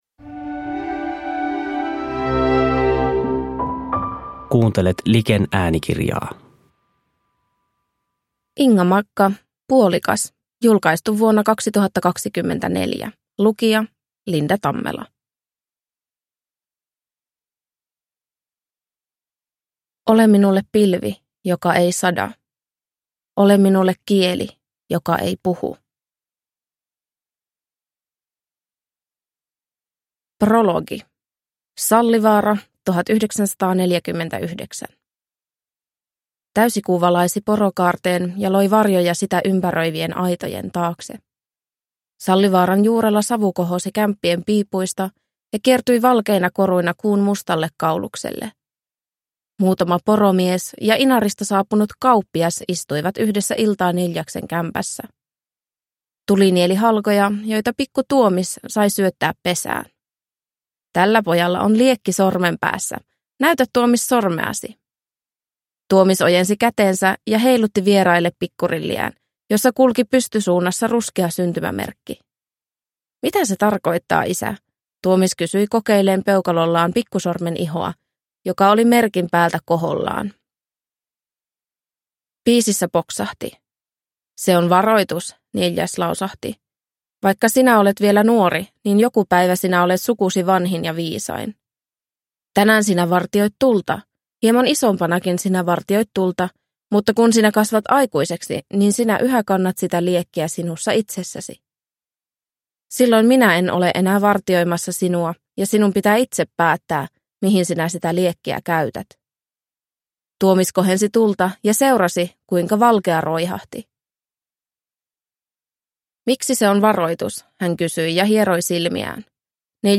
Puolikas (ljudbok) av Inga Magga